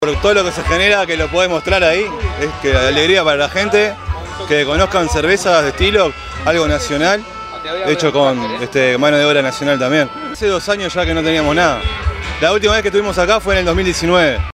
Fiesta de la Cerveza Artesanal en Parque del Plata